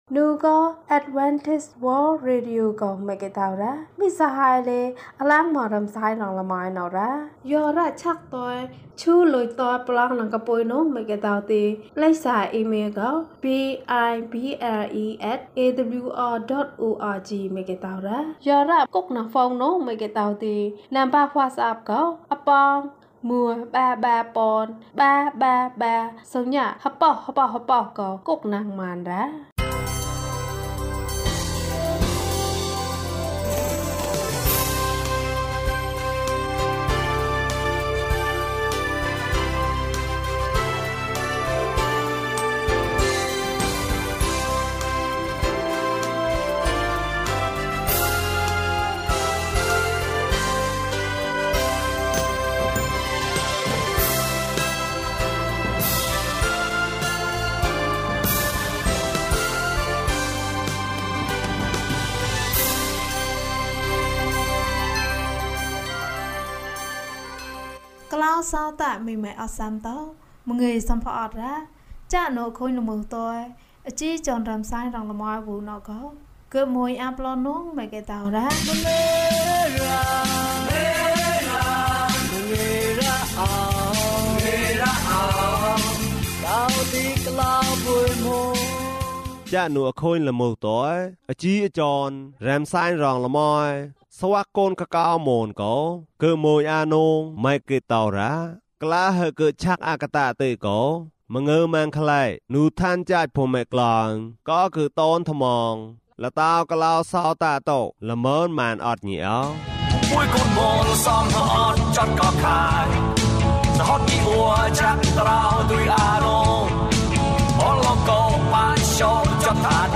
ခရစ်တော်ထံသို့ ခြေလှမ်း ၂၂။ ကျန်းမာခြင်းအကြောင်းအရာ။ ဓမ္မသီချင်း။ တရားဒေသနာ။